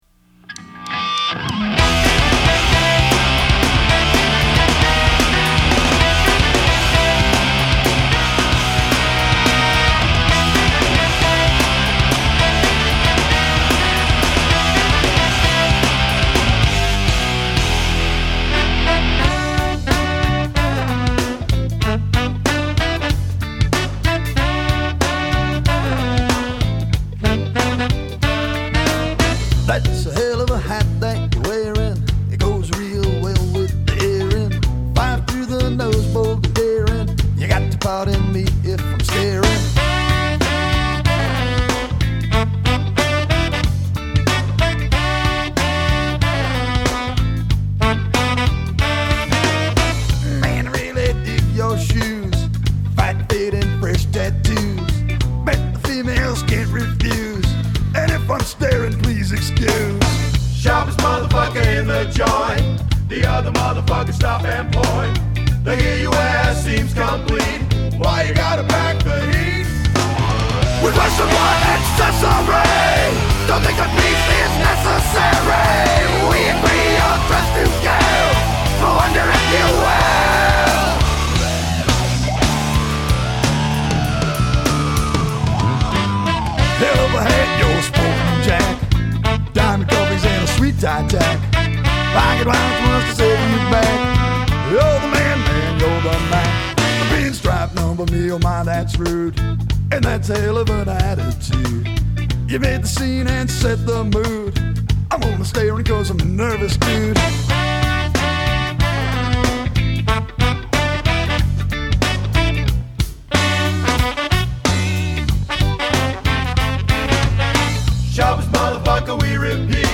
But that horn part.